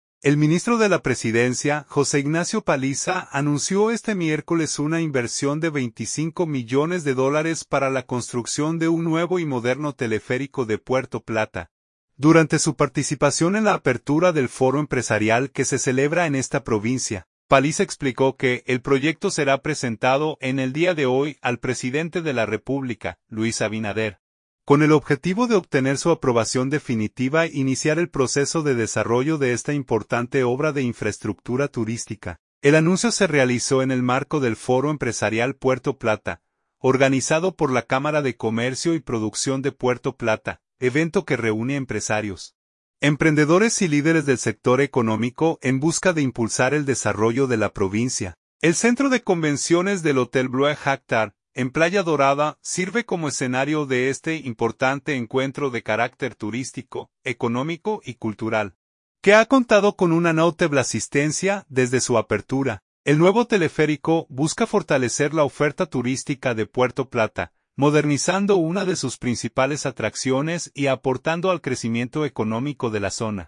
InfoENN – El Nuevo Norte || Puerto Plata.– El ministro de la Presidencia, José Ignacio Paliza, anunció este miércoles una inversión de 25 millones de dólares para la construcción de un nuevo y moderno Teleférico de Puerto Plata, durante su participación en la apertura del Foro Empresarial que se celebra en esta provincia.